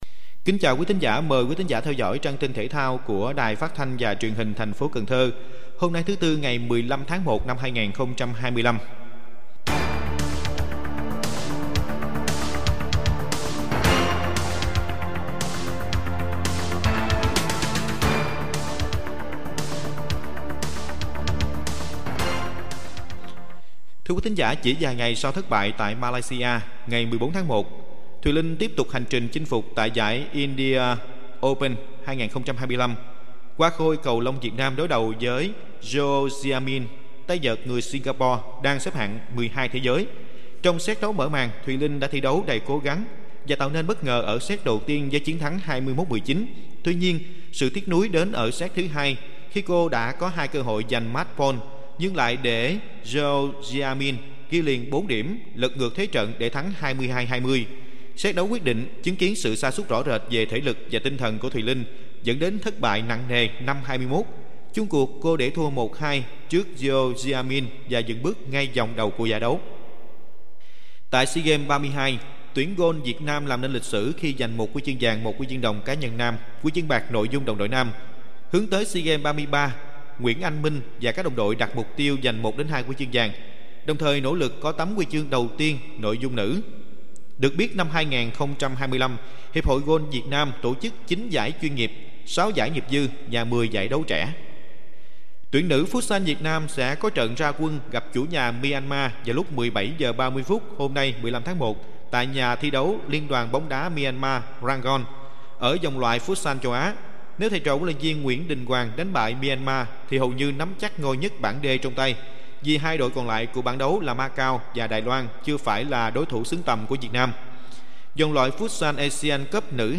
Bản tin thể thao 15/1/2025